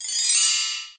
cf_belltree.ogg